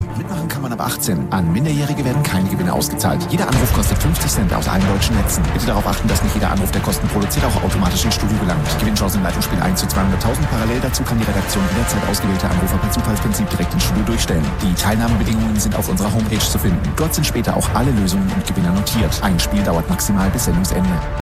Die Zahl wird auch im Programm genannt, im Rahmen der besonders schnell gesprochenen und teilweise wegen dröhnender Hintergrundmusik kaum verständlichen Spielregeln.
spielregeln.m4a